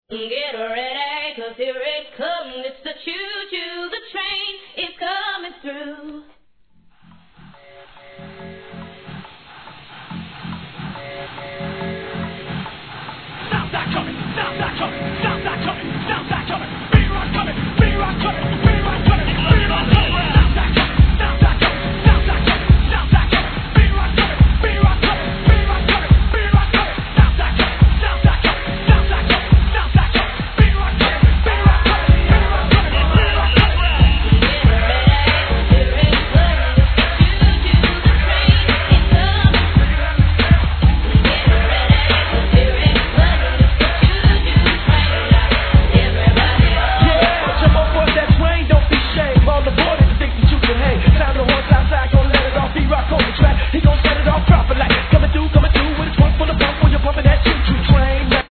G-RAP/WEST COAST/SOUTH
いなたいジャケのイメージを見事に裏切る1996年のマイアミ調BOUNCE!!